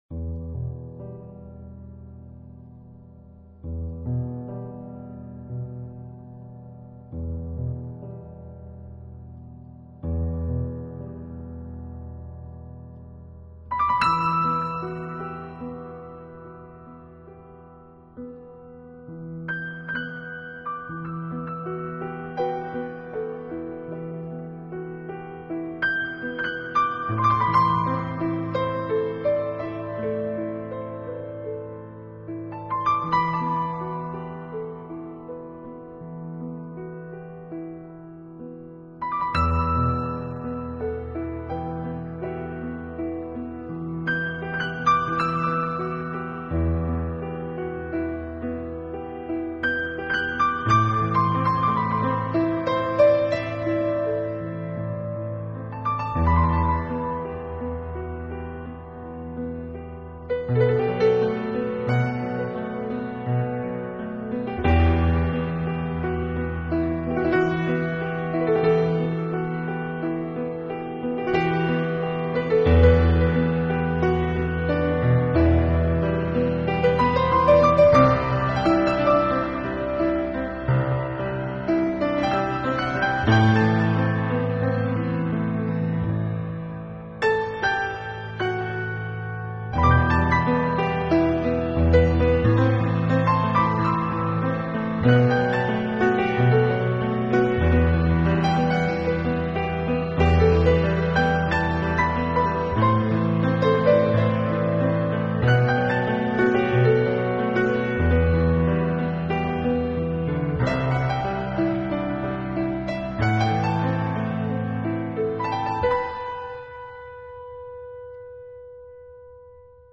音乐类型：钢琴